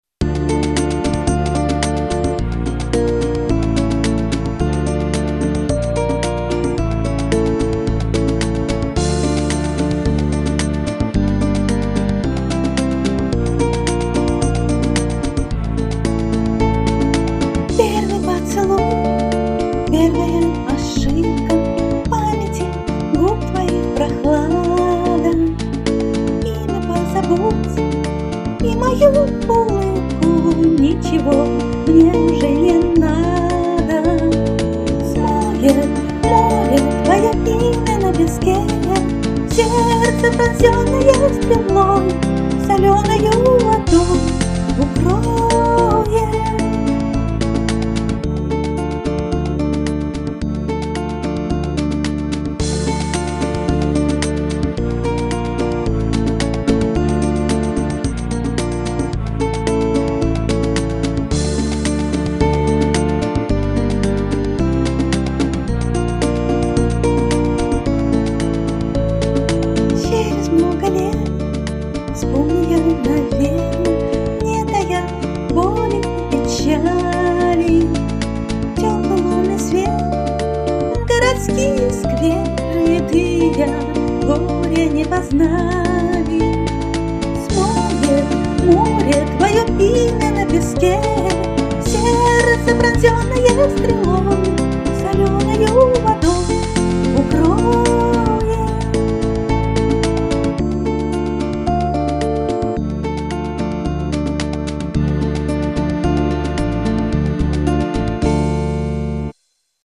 Эскиз песни в стиле поп-музыки Категория: Написание музыки
Демонстративный "сырой" вариант песни.